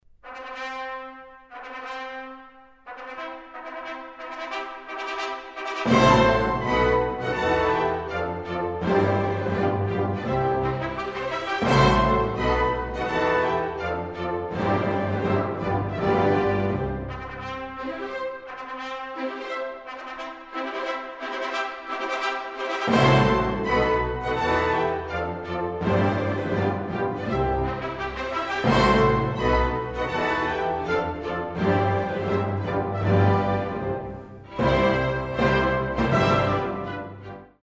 でも、実際にここのアウフタクトをなくした演奏もありました。